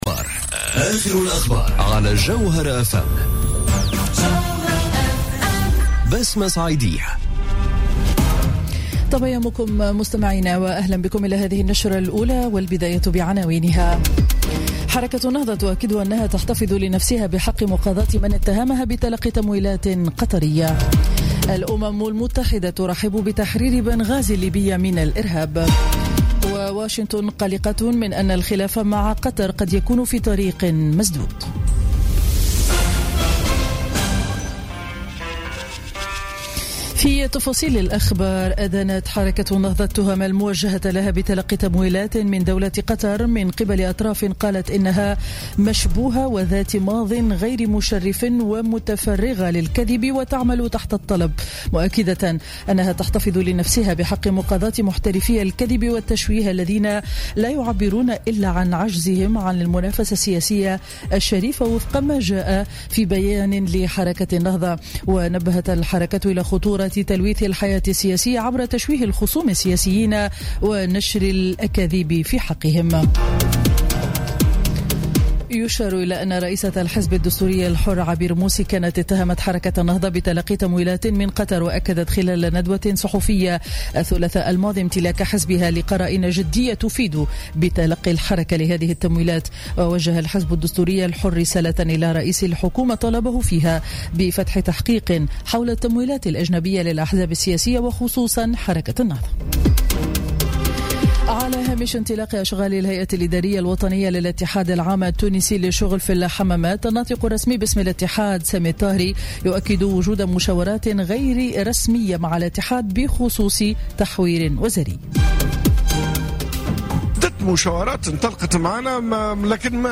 نشرة أخبار السابعة صباحا ليوم الجمعة 7 جويلية 2017